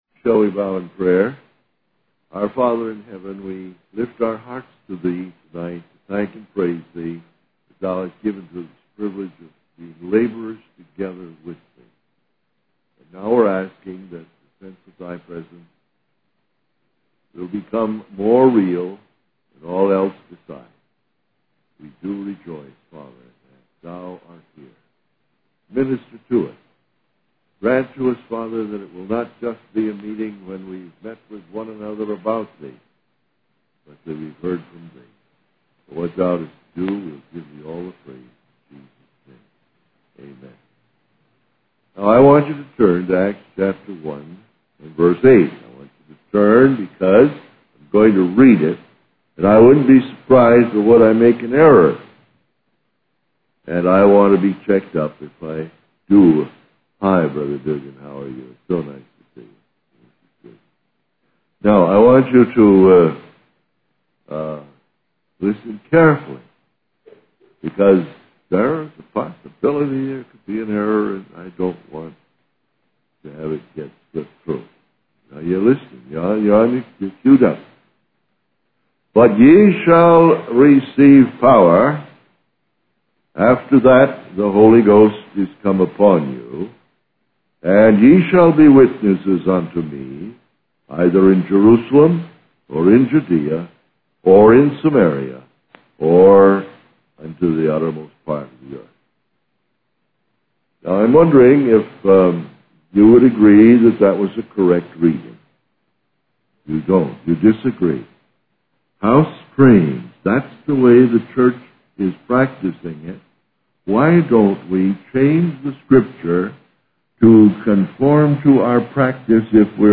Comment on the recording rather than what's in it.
In this sermon, the speaker addresses a group of people and asks them if they have ever been lost.